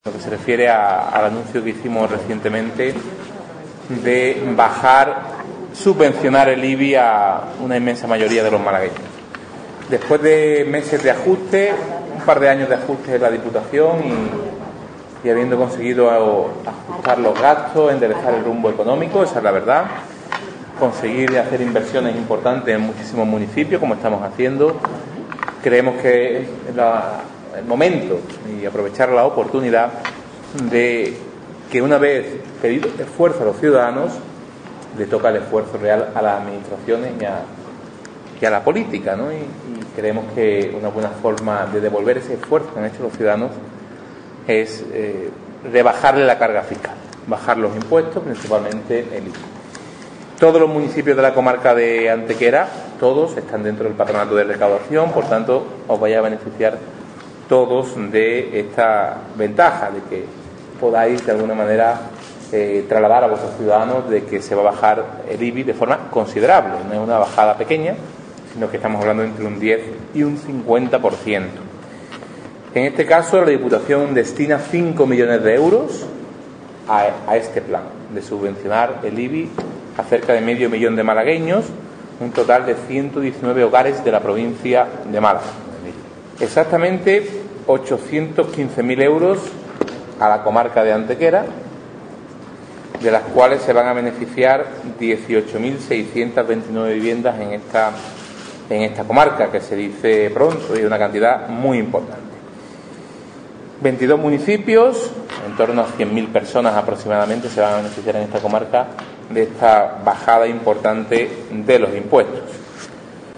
El presidente de la institución provincial, Elías Bendodo, ha anunciado la medida a los alcaldes de estas zonas en el Salón de los Reyes del Ayuntamiento de Antequera, donde ha comparecido para explicar la medida junto al alcalde de Antequera, Manolo Barón.
Cortes de voz